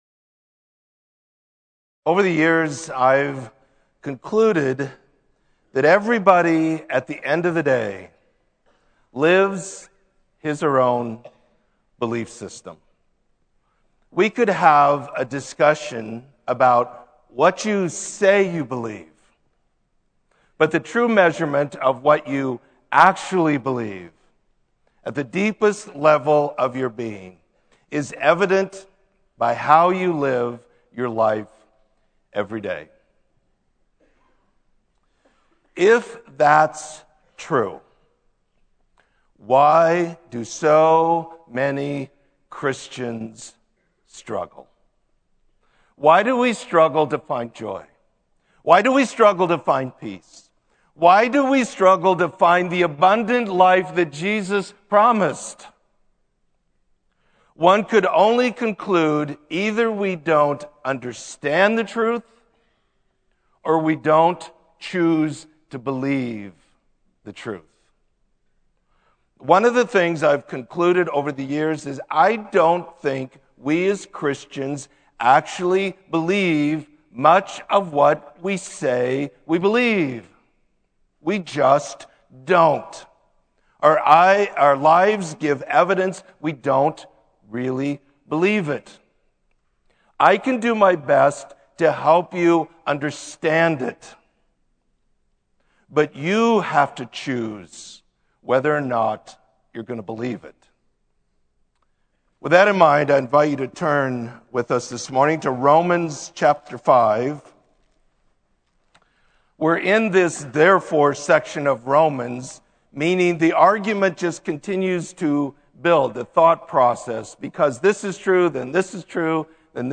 Sermon: Through Christ